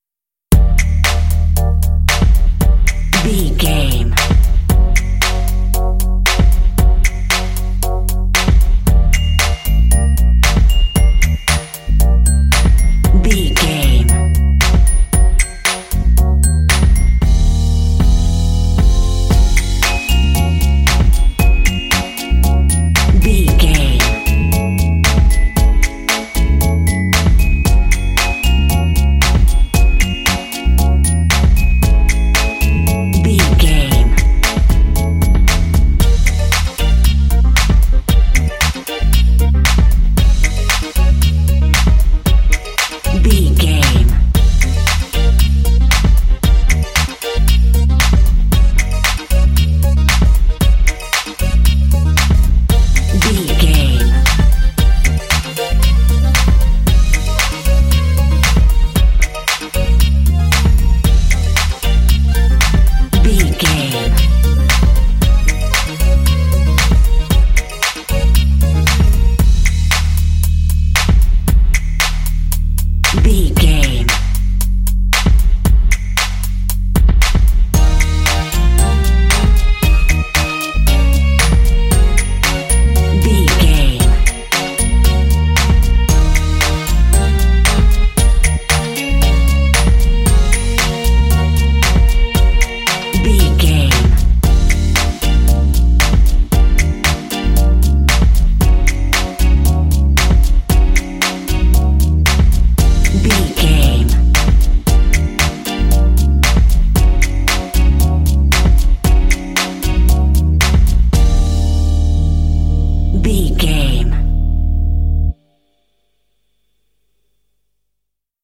Aeolian/Minor
D
funky
happy
bouncy
groovy
bass guitar
electric guitar
drums
synthesiser
strings
Funk